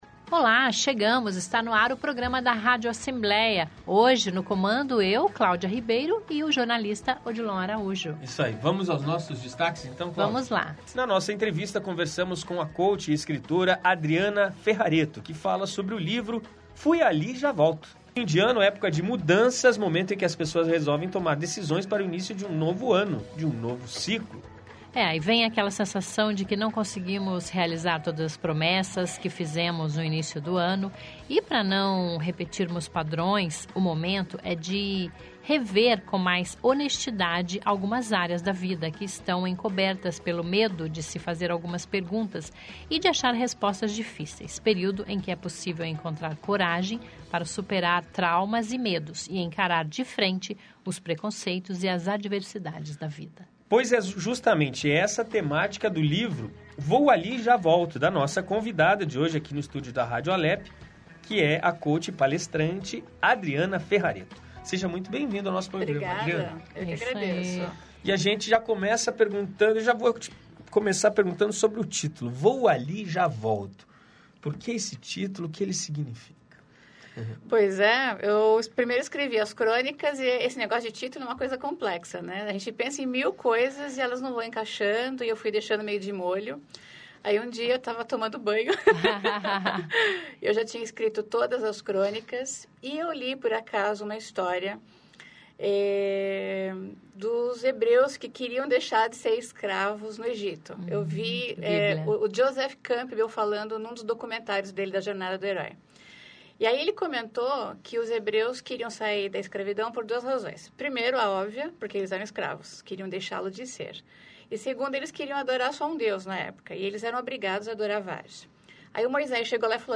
A entrevista está ótima.